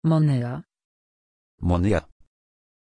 Pronunciation of Monya
pronunciation-monya-pl.mp3